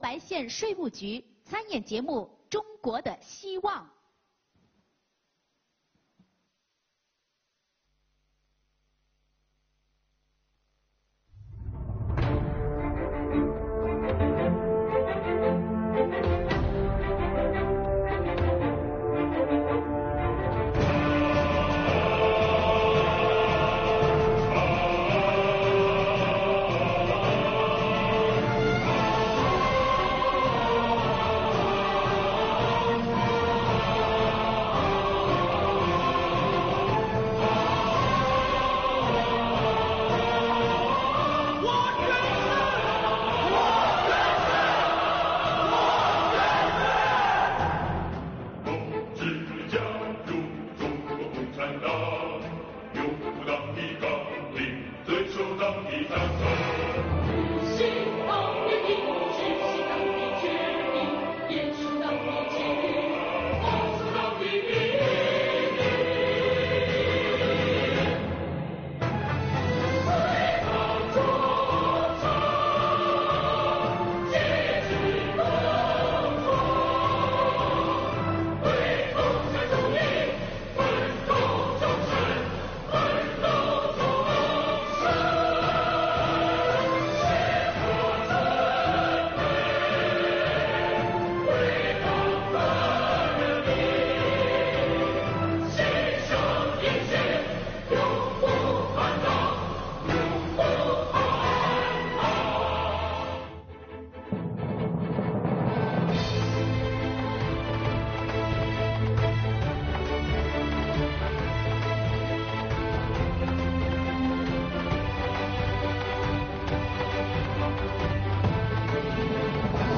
在博白，国家税务总局博白县税务局参加了玉林市“永远跟党走”庆祝中国共产党成立 100 周年暨第九届全区基层群众文艺会演，通过舞蹈《中国的希望》演绎了党的奋斗历史，献礼建党100周年。
（视频为博白县税务局青年干部进行舞蹈表演）